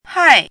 chinese-voice - 汉字语音库
hai4.mp3